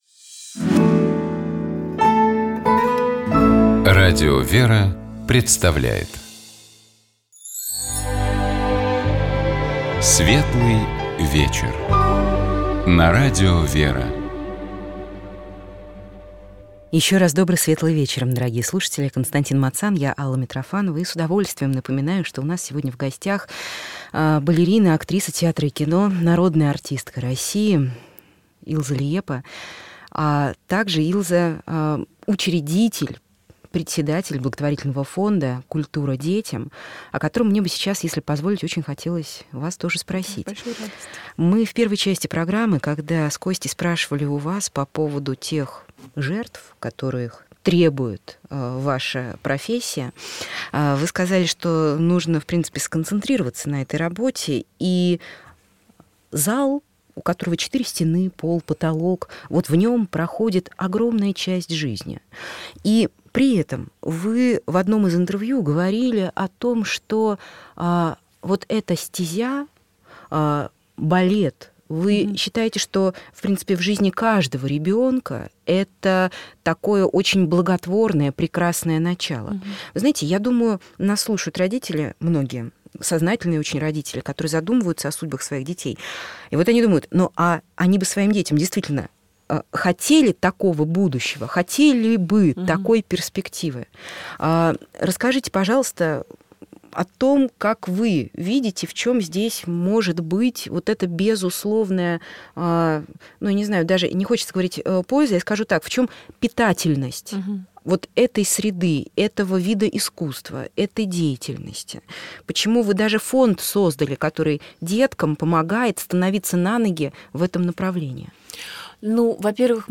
У нас в гостях балерина, Народная артистка России Илзе Лиепа. Мы говорим о творчестве, о вере и промысле Божьем в жизни нашей гостьи. Илзе рассказала нам о своём проекте - благотворительном фонде «Культура детям», который поддерживает юные таланты из разных уголков нашей страны.